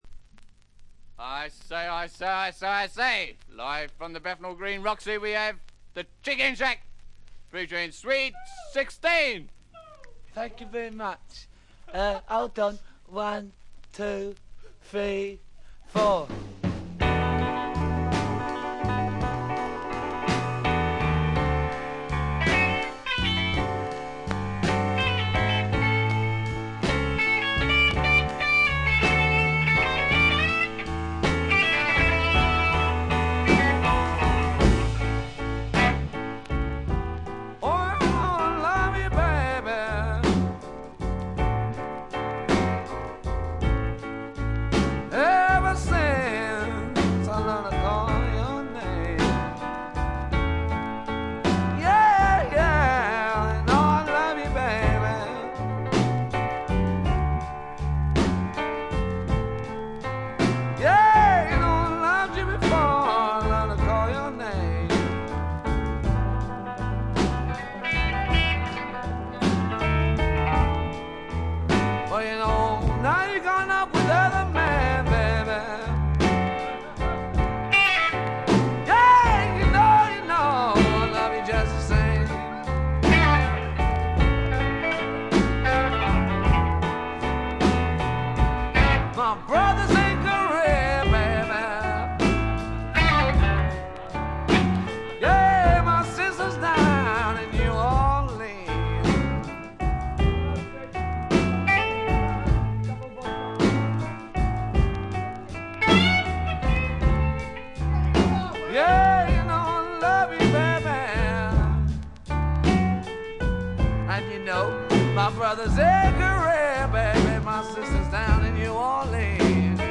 チリプチや散発的なプツ音はそこそこ出ますが鑑賞を妨げるようなものではありません。
英国ブルースロック名作中の名作。
初期モノラル・プレス。
試聴曲は現品からの取り込み音源です。（ステレオ針での録音です）